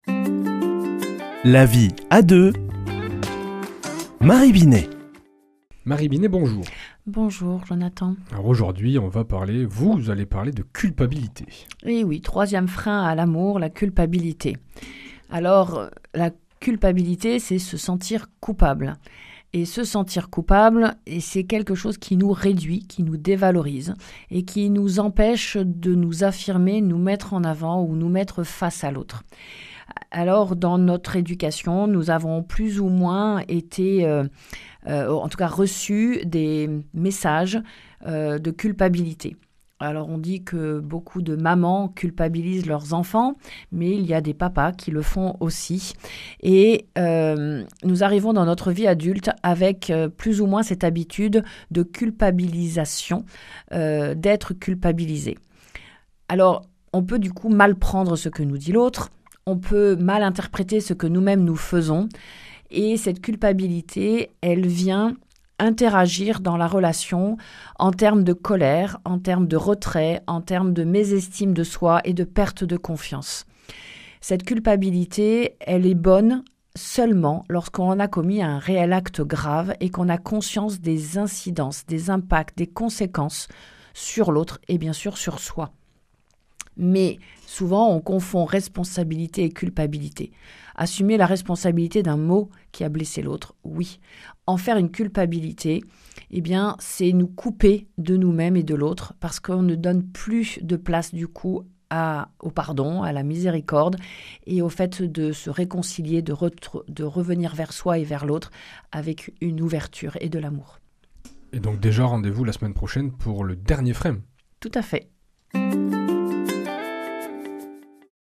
mardi 4 novembre 2025 Chronique La vie à deux Durée 4 min
Une émission présentée par